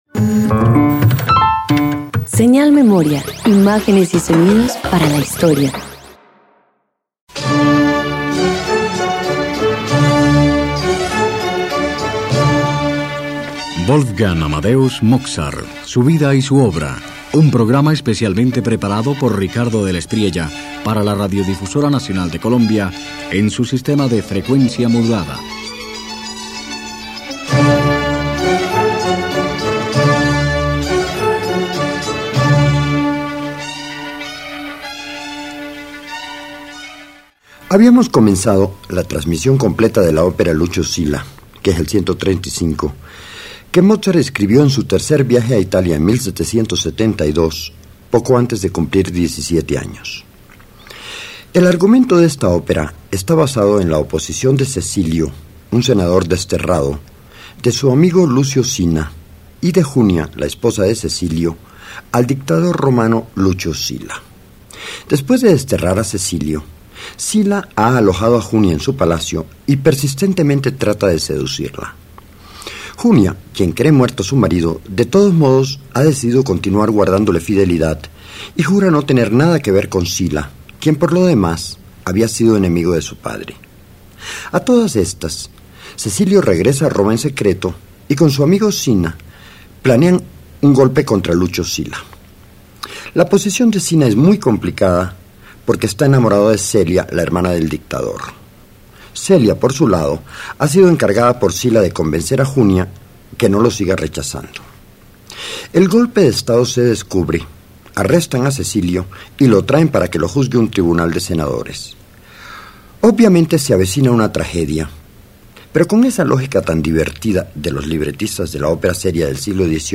Sina decide tomar justicia por sus propias manos y asesinar a Silla, mientras Mozart transforma un material musical convencional en un aria llena de energía y tensión dramática, demostrando su ingenio incluso en medio de recursos simples.
070 Opera Lucio Silla Parte IV_1.mp3